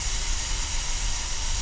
gas_leak_04_loop.wav